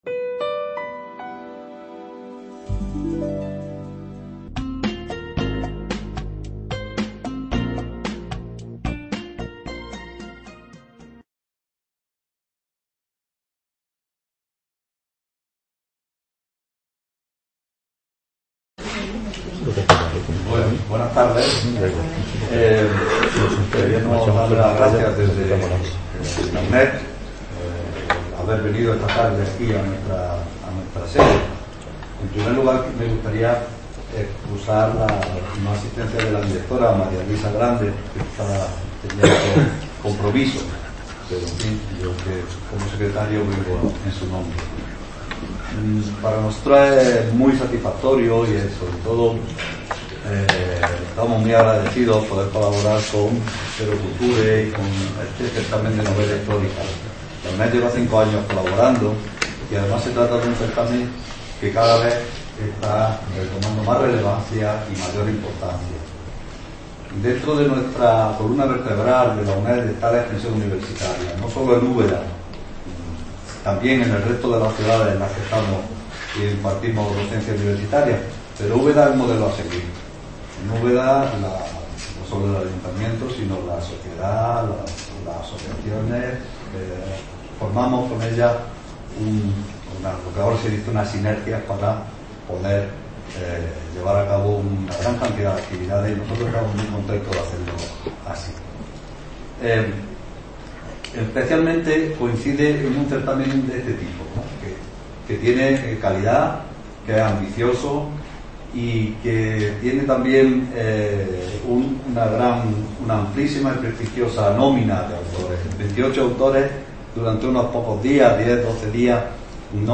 En el contexto de la X Edición del Certamen Internacional de Novela Histórica Ciudad de Úbeda